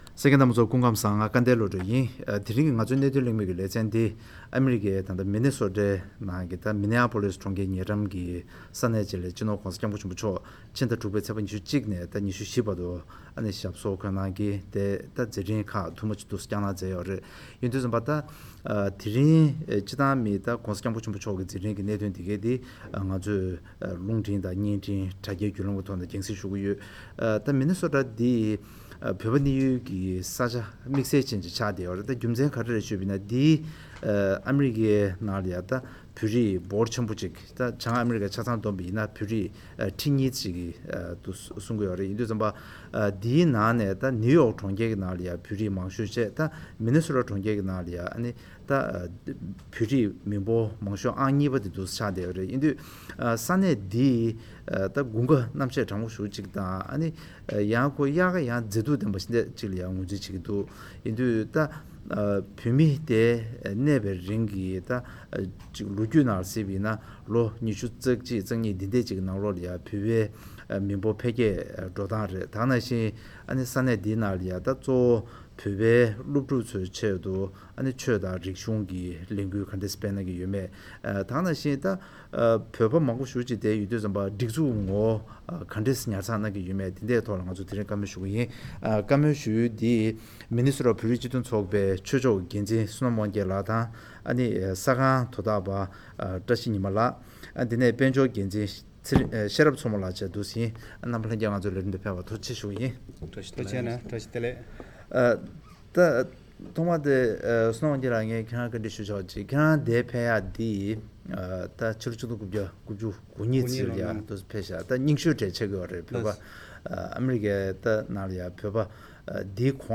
ཨ་རིའི་མི་ནི་སོ་ཊ་མངའ་སྡེའི་ནང་བོད་མི་ཐོག་མ་འབྱོར་བ་ནས་བཟུང་ད་བར་འཕེལ་རྒྱས་འབྱུང་ཚུལ་གྱི་ལོ་རྒྱུས་དང་བོད་རིགས་སྤྱི་མཐུན་ཚོགས་པའི་ངོ་བོའི་ཐད་གླེང་མོལ།